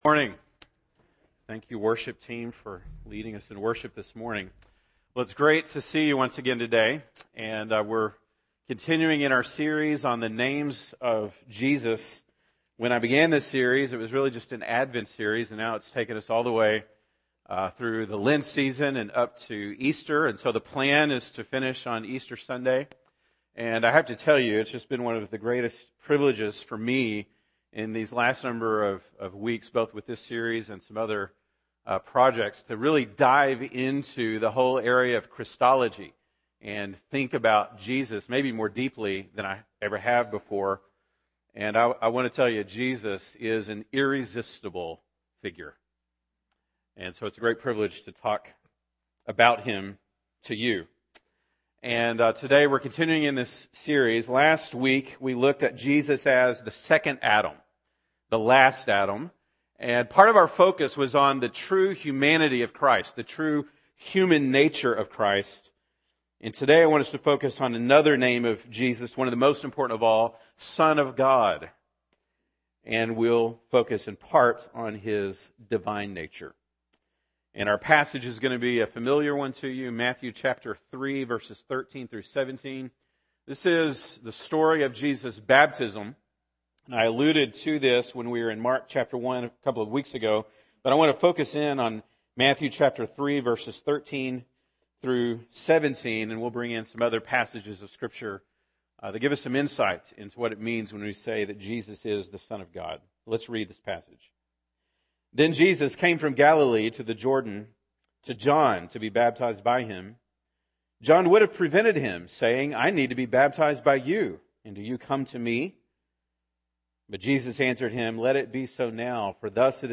March 13, 2016 (Sunday Morning)